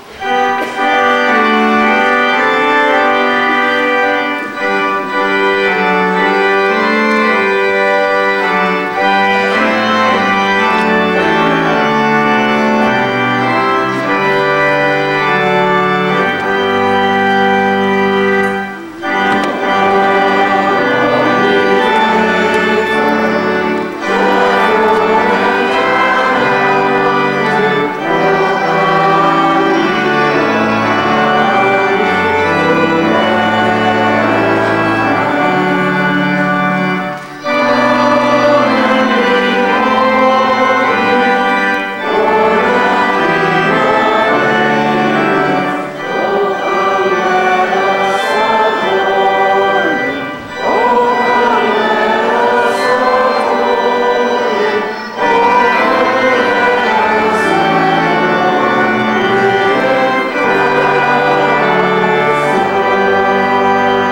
Organ at Christmas time